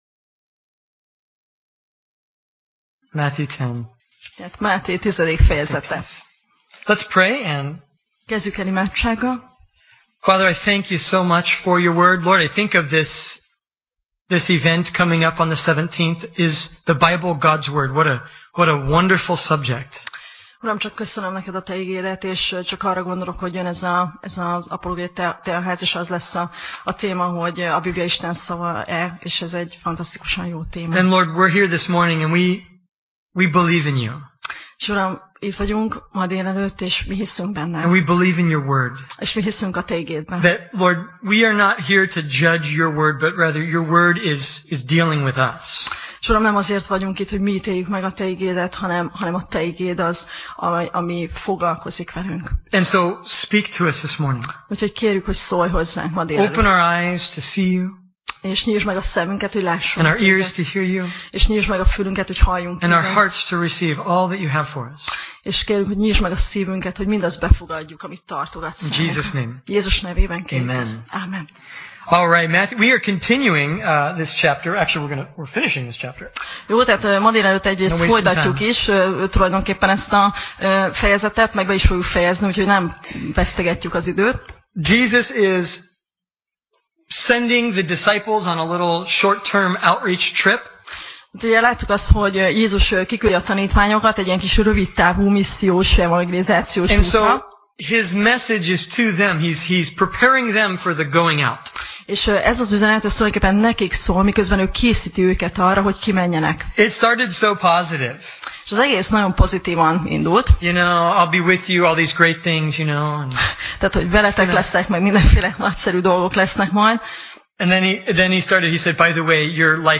Máté Passage: Máté (Matthew) 10:25–42 Alkalom: Vasárnap Reggel